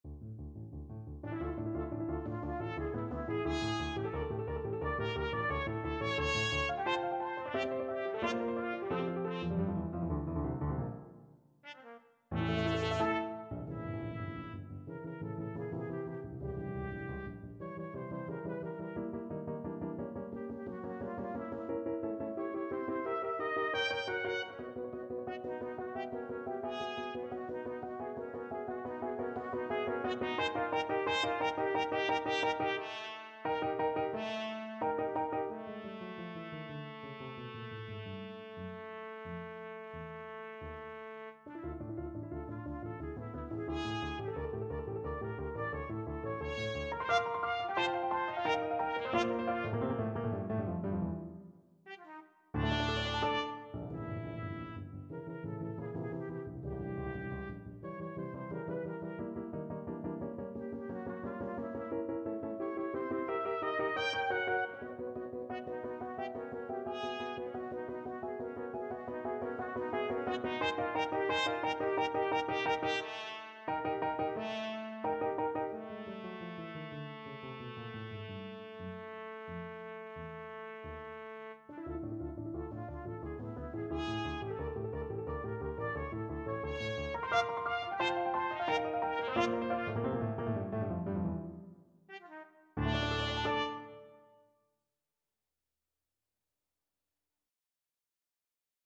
Trumpet version
2/2 (View more 2/2 Music)
Allegro molto = 176 (View more music marked Allegro)
Classical (View more Classical Trumpet Music)